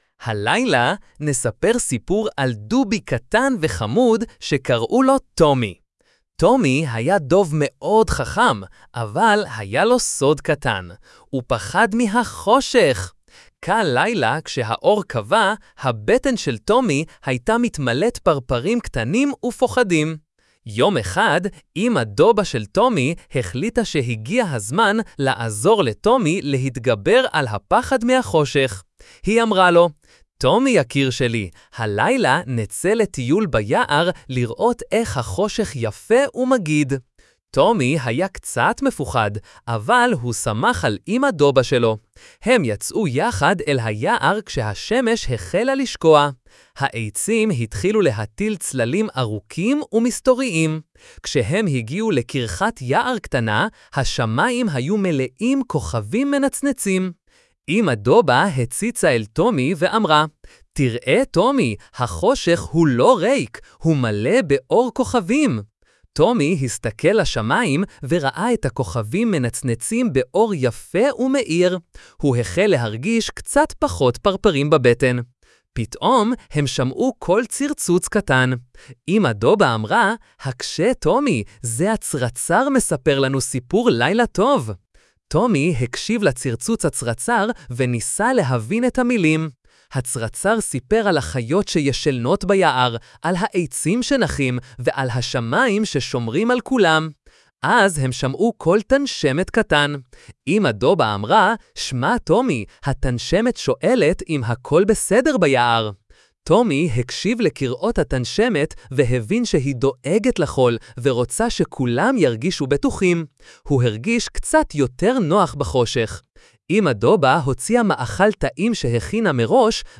שימו  כדאי לבקש ממנו לנקד את הסיפור כרגע הוא יוצר סיפורים באורך מסוים ומשאיר בסוף כמה דקות של שקט אני ישתדל לסדר את זה בהמשך